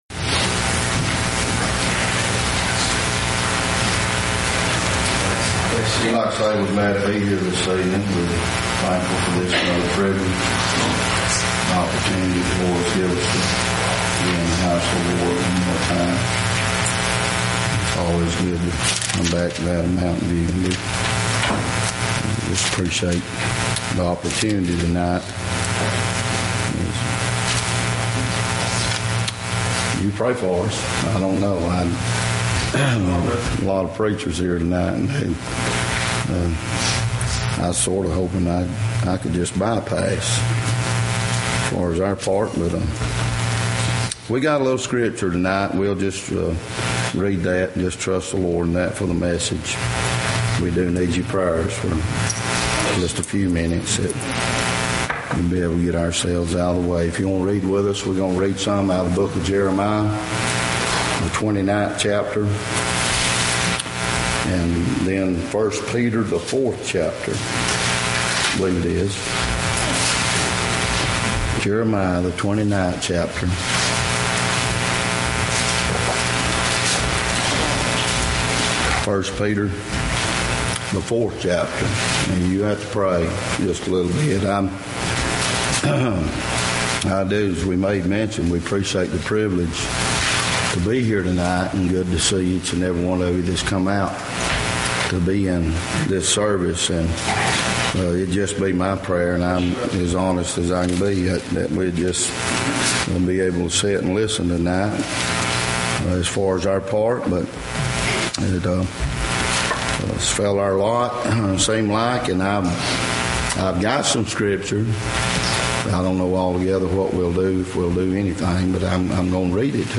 Preachers meeting in 2013